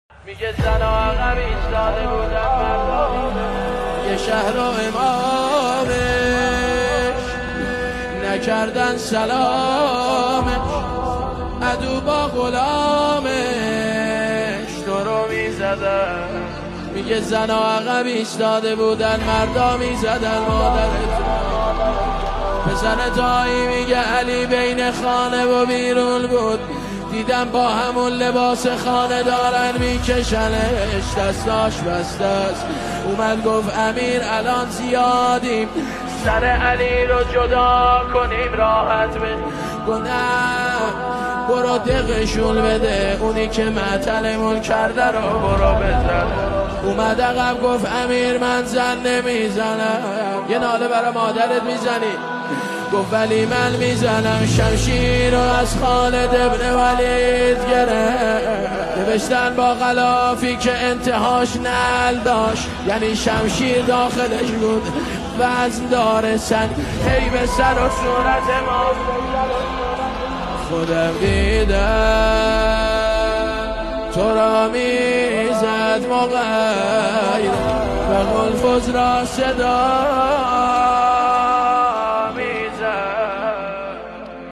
مداحی مذهبی